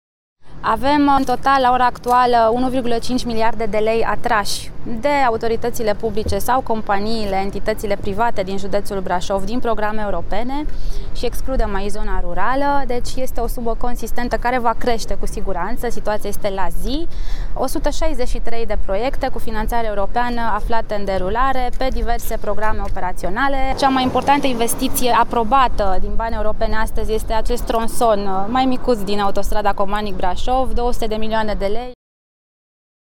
Ministrul Roxana Mînzatu: